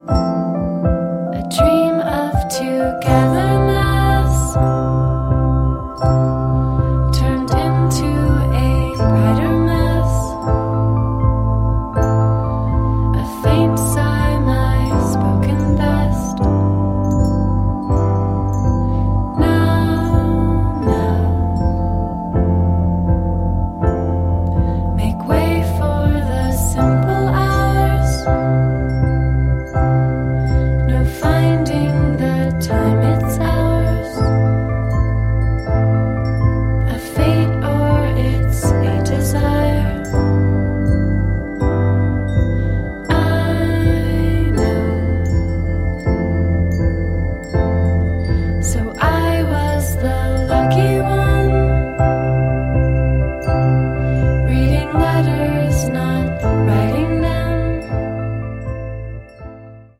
świeżym, marzycielskim tercecie synth-popowym
Niewinne, delikatne hymny dziewcząt